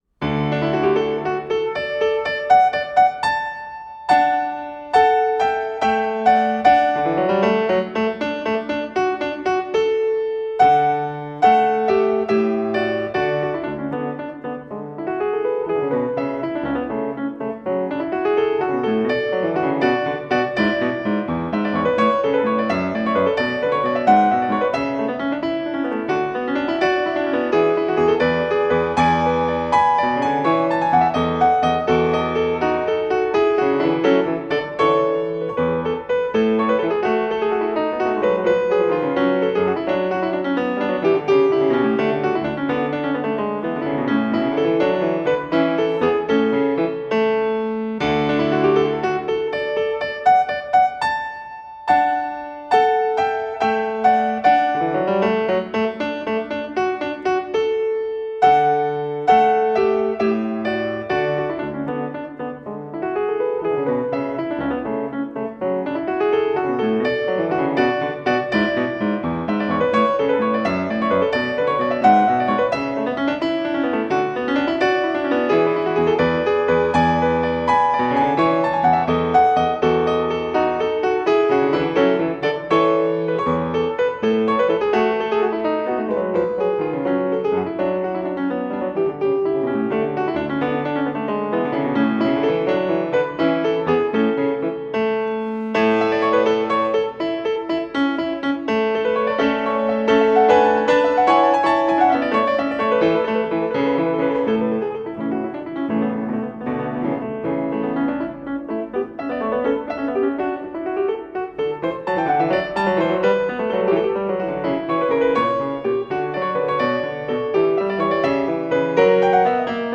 Piano version
Classical Piano